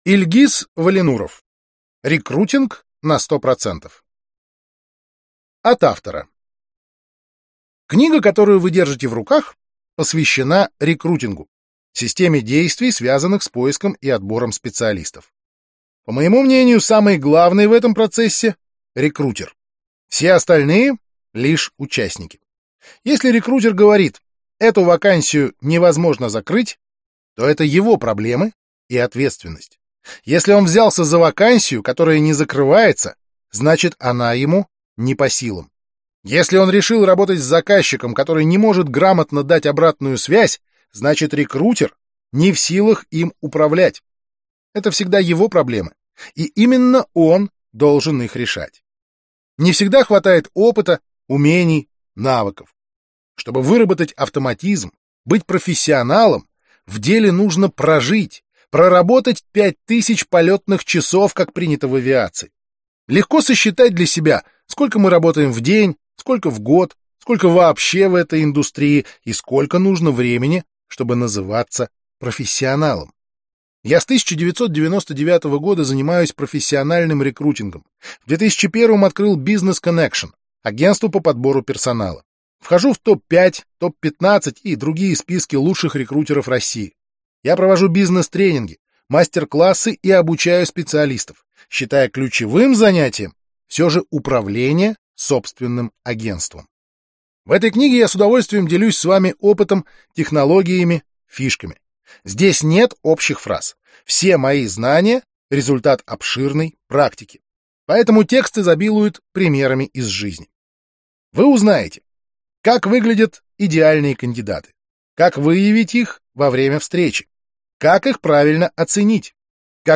Аудиокнига Рекрутинг на 100 %. Искусство привлекать лучших | Библиотека аудиокниг